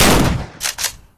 Index of /noise_WeaponSound/sfx/
escopeta.mp3